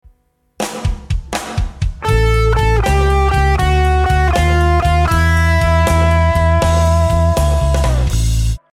Walking Down Chromatic Lick
The walking down version of a blues chromatic lick starts with the root note of the I chord then walks down chromatically from the 7th of the I chord to the root note of the V chord. In the key of A this is the root note of A followed by the 7th note of G walking down chromatically to the root of the V chord (E).
blues_turnaround_lick02.mp3